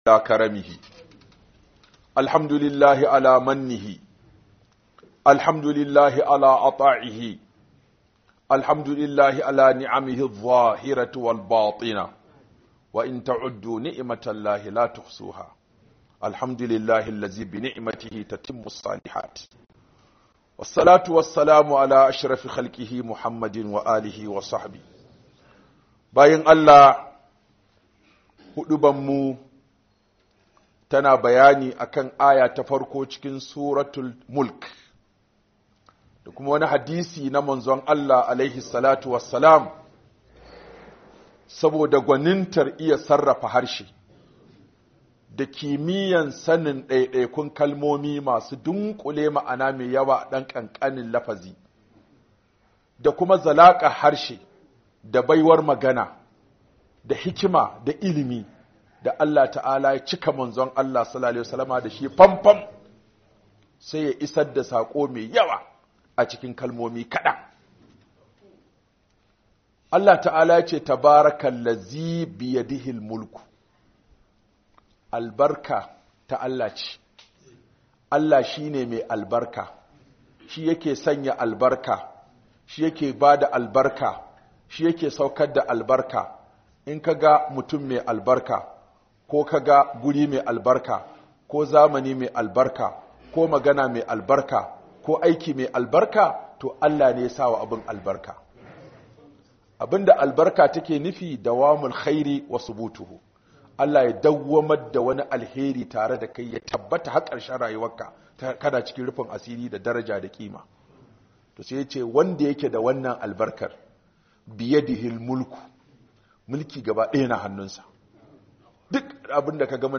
Aya Farko Ta Suratul Mulk - Huduba by Sheikh Aminu Ibrahim Daurawa